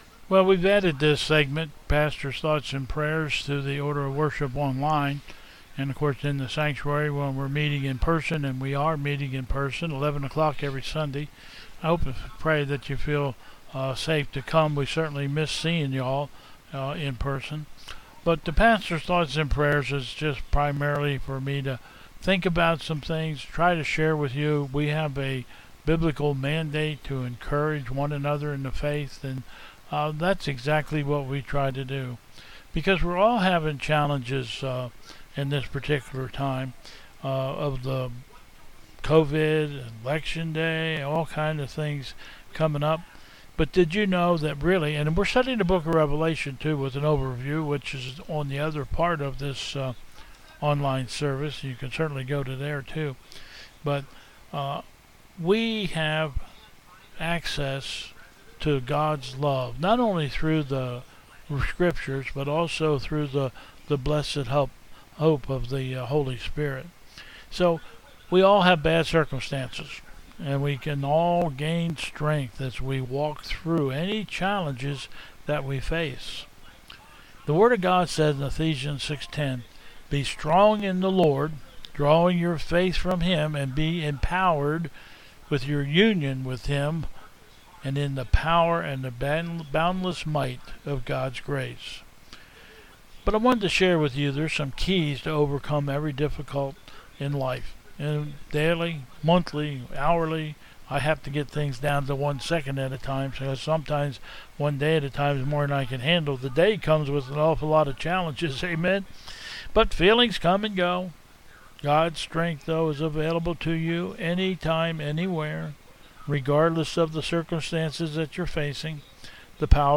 Bethel 10/25/20 Service
Processional